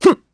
Siegfried-Vox_Jump_kr.wav